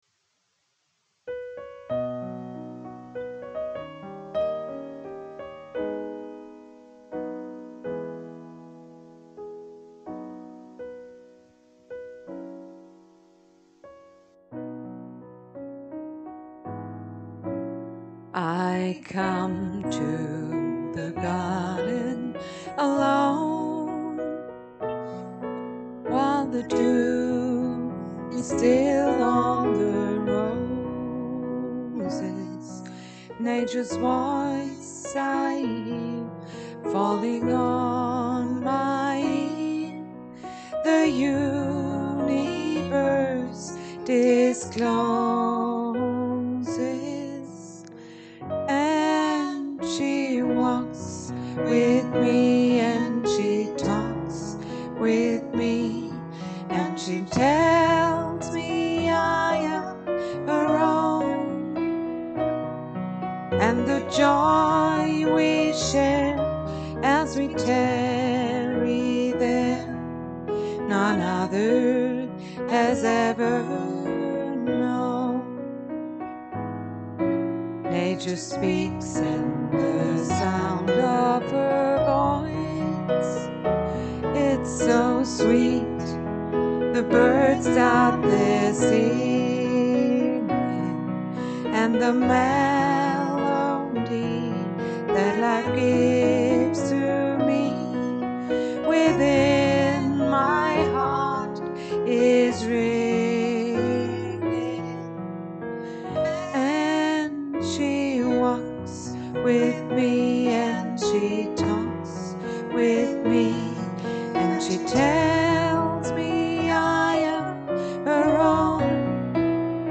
Because, as I already noticed, the music is a bit too loud during the recording, I was wondering if it would be possible to change this.
I'm not really satisfied with the result, because I simply don't have a suitable microphone.